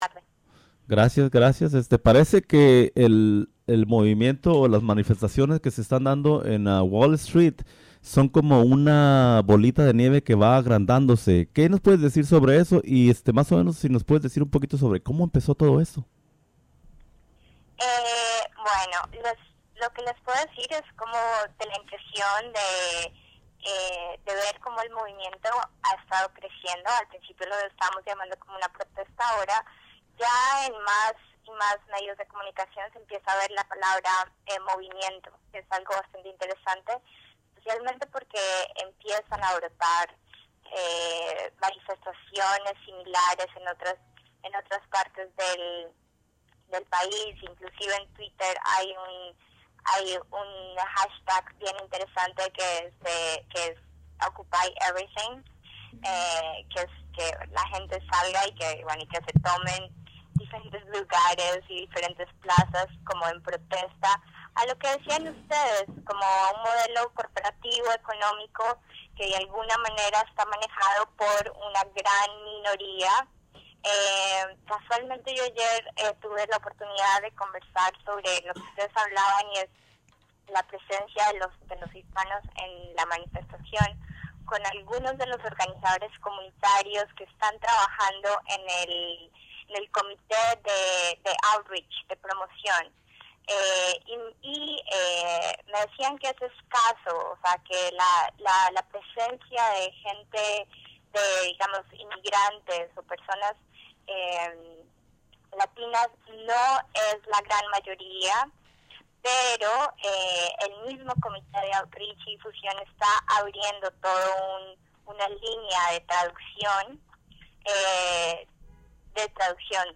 Interview on WGXC Afternoon Show.